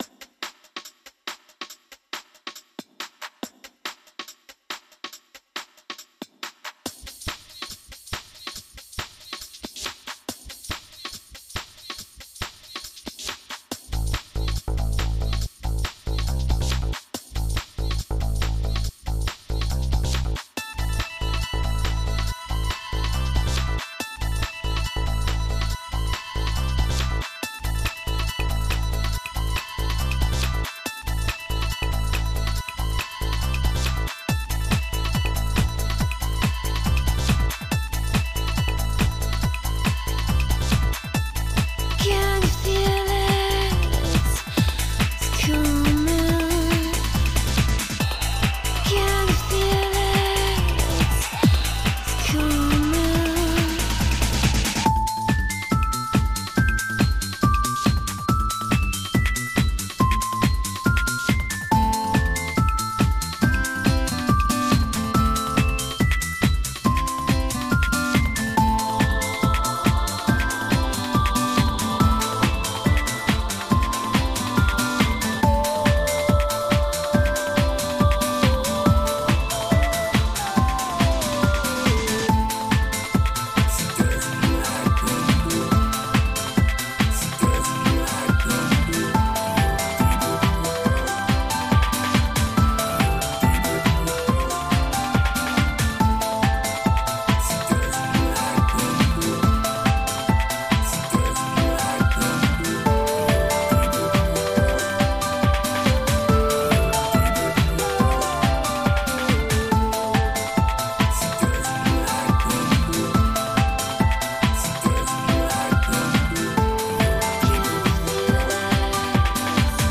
in diesem Retrostyle zu produzieren.